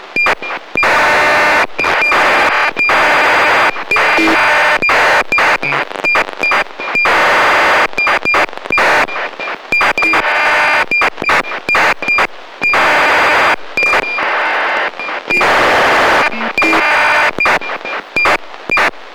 ACARS в Москве в 18.00 на 131,725